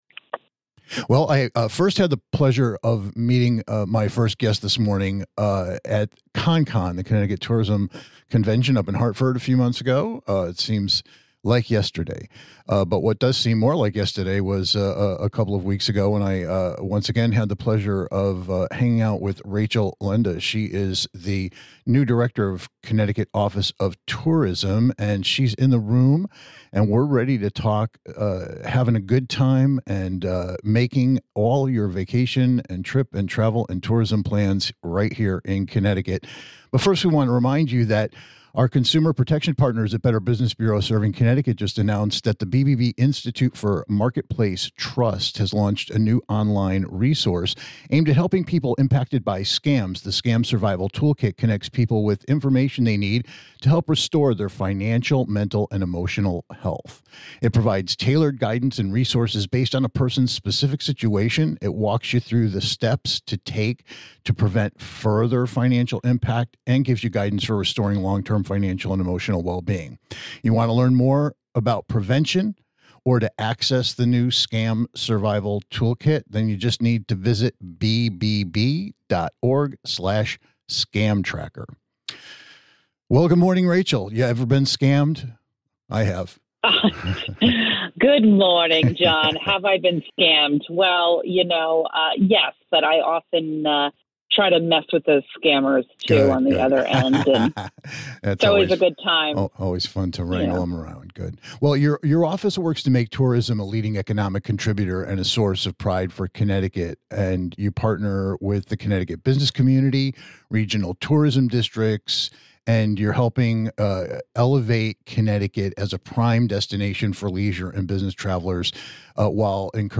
We'll clue you in as For the People chats up Connecticut's new uniquely experienced and home grown Director of Tourism!